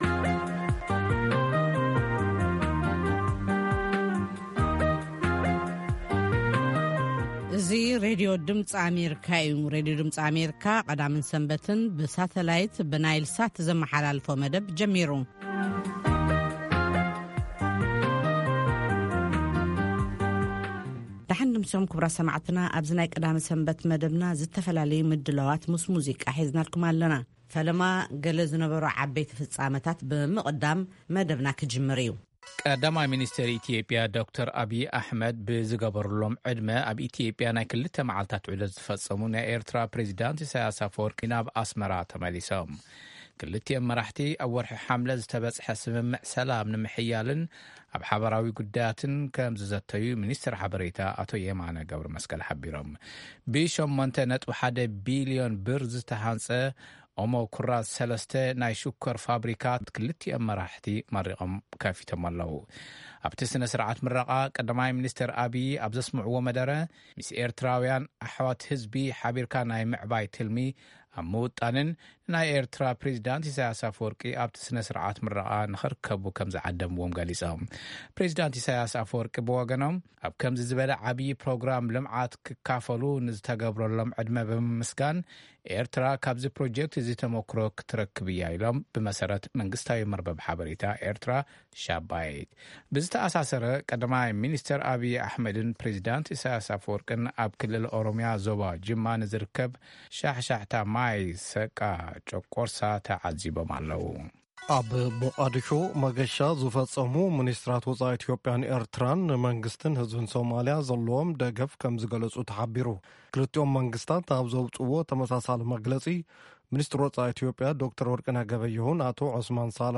ድምጺ ኣሜሪካ ፈነወ ቛንቛ ትግርኛ ካብ ሶኑይ ክሳብ ሰንበት ይፍነው። ፈነወ ቛንቛ ትግርኛ ካብ ሶኑይ ክሳብ ዓርቢ ብዕለታዊ ዜና ይጅምር፥ እዋናዊ ጉዳያትን ሰሙናዊ መደባት'ውን የጠቓልል ።ቀዳምን ሰንበትን ኣብቲ ሰሙን ዝተፈነው መደባት ብምድጋም ፈነወ ቛንቛ ትግርኛ ይኻየድ።